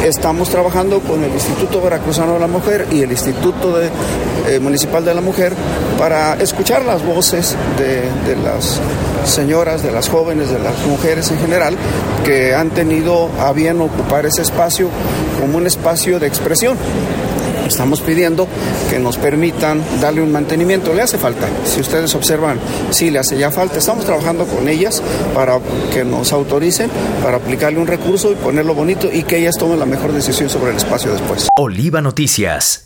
En entrevista, comentó que, dicho tramo ubicado justo a requiere intervención urgente por sus condiciones actuales.
02_alberto_islas.wav